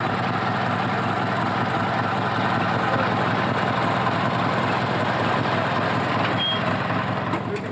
Toro Ride On Mower Run And Shut Down